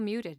Provide shorter audio prompts for muted/unmuted
conf-muted.wav